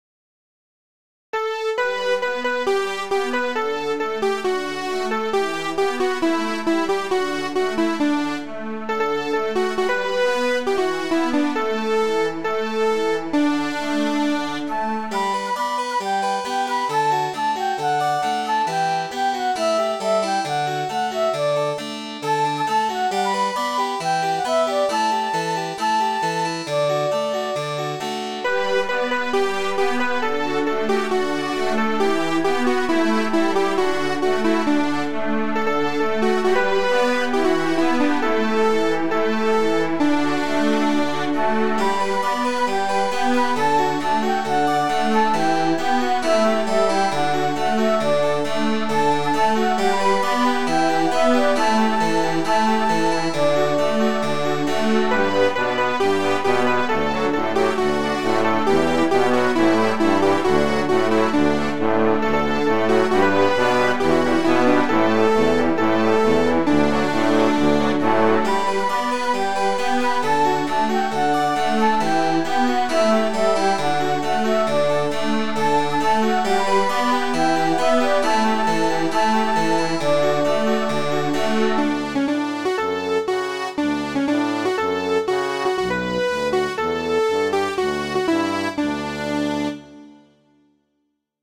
Midi File, Lyrics and Information to Madam, Will You Walk?